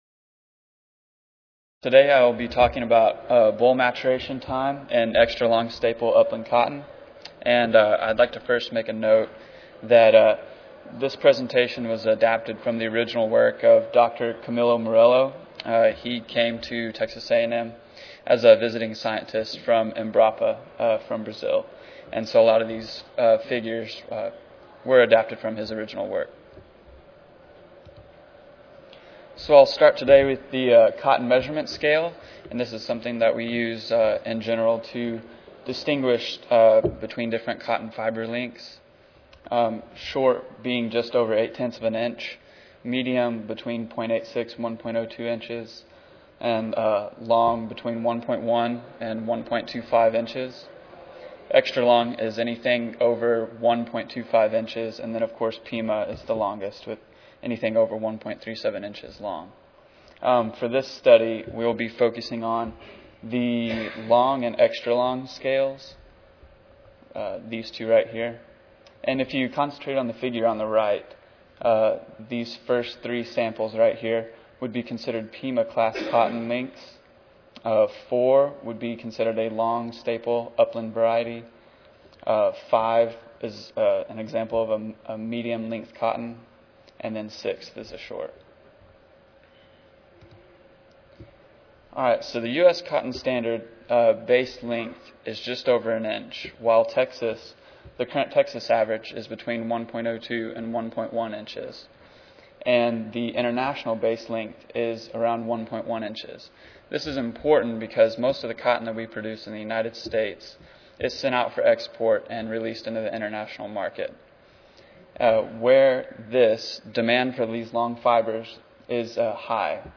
C01 Crop Breeding & Genetics Session: Crop Breeding and Genetics: Cotton (ASA, CSSA and SSSA Annual Meetings (San Antonio, TX - Oct. 16-19, 2011))
Texas A&M University Recorded Presentation Audio File